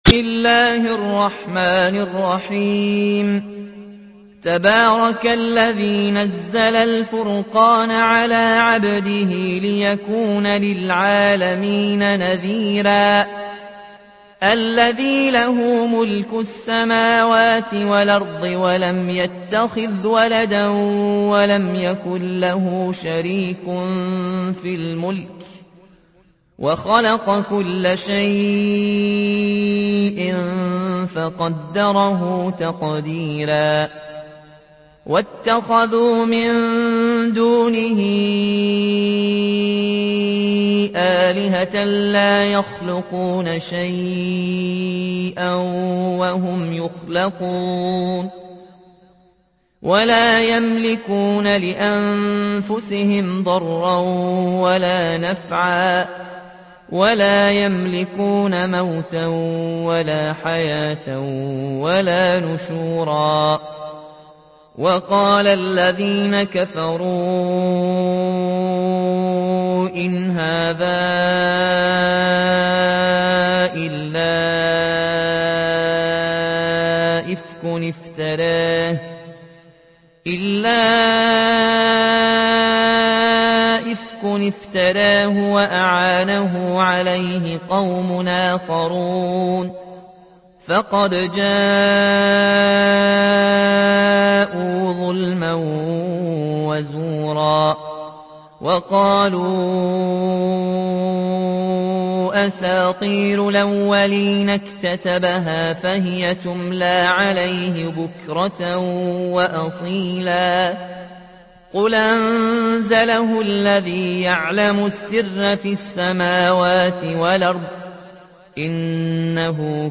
تحميل : 25. سورة الفرقان / القارئ ياسين الجزائري / القرآن الكريم / موقع يا حسين